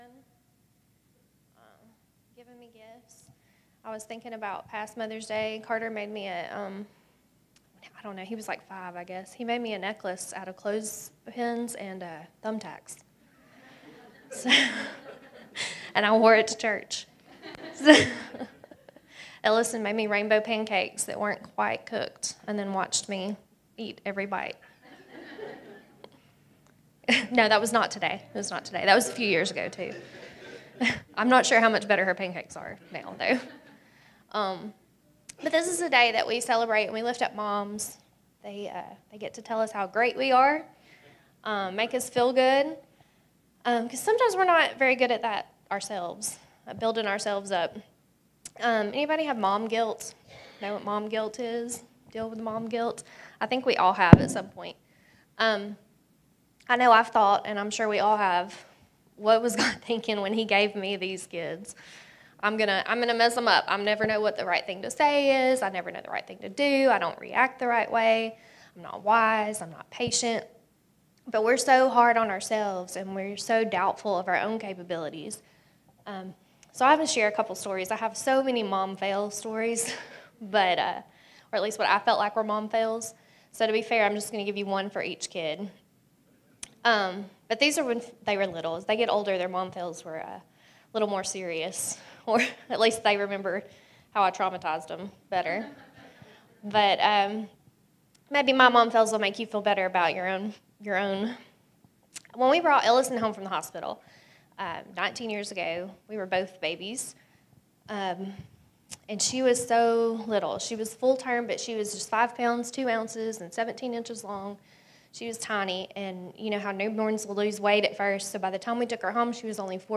Sermons | Real Life Community Church